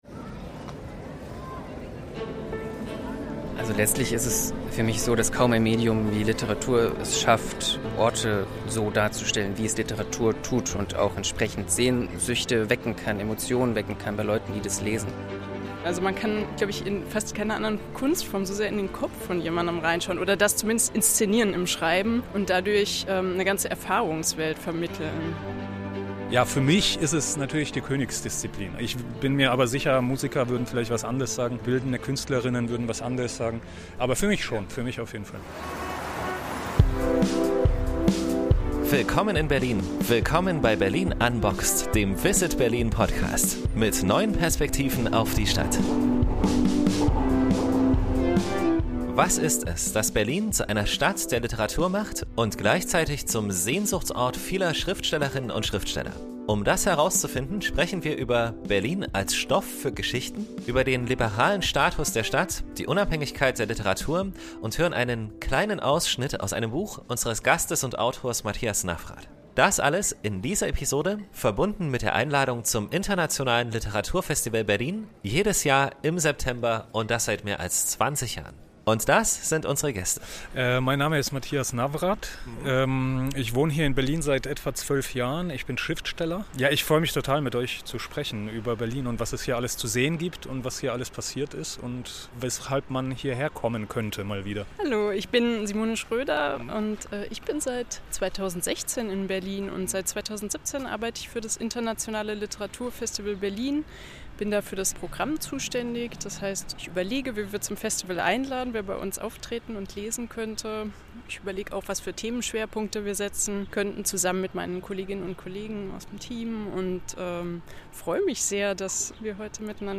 In dieser Episode von Berlin Unboxed geht es um Berlin als Literaturstadt. Wir nehmen euch mit auf einen Spaziergang zu einigen der vielen, vielen literarisch spannenden Orte in Berlin.